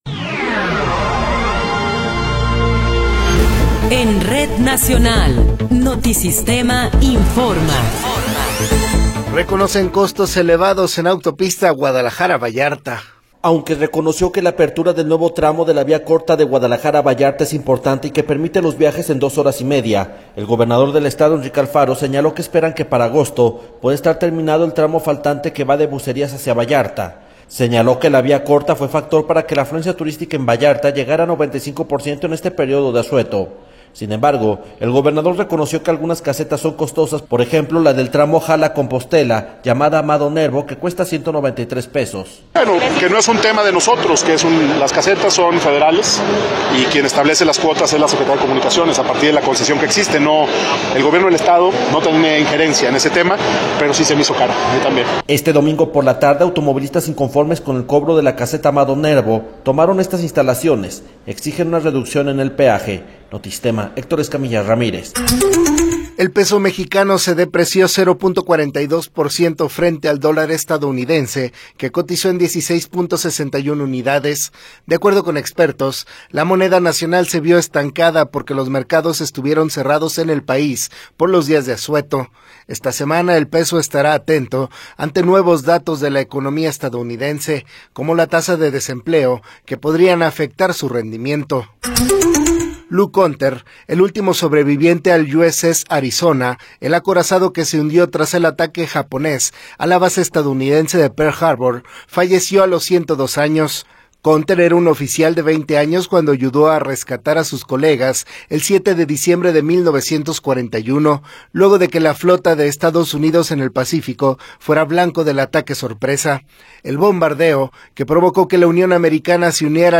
Noticiero 19 hrs. – 1 de Abril de 2024
Resumen informativo Notisistema, la mejor y más completa información cada hora en la hora.